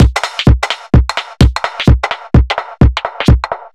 Index of /musicradar/uk-garage-samples/128bpm Lines n Loops/Beats
GA_BeatFiltC128-05.wav